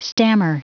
Prononciation du mot stammer en anglais (fichier audio)
Prononciation du mot : stammer